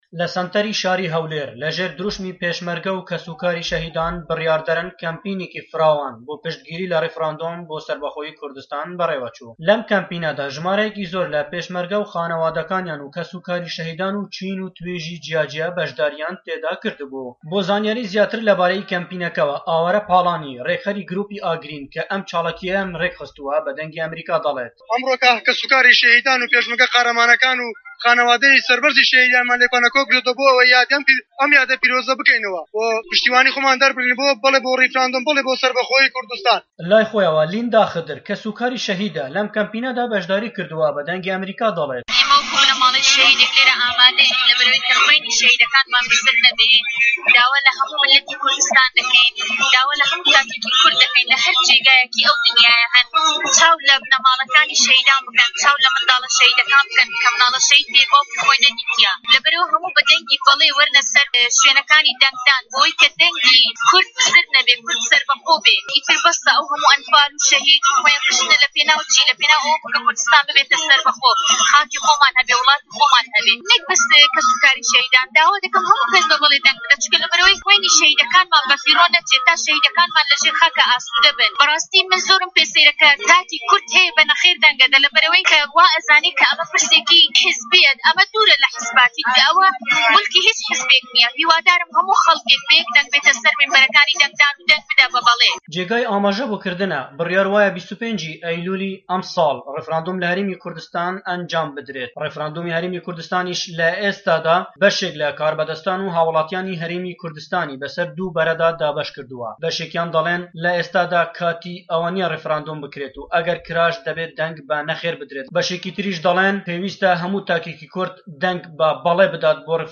دەقی ڕاپۆرتەکەی پەیامنێرمان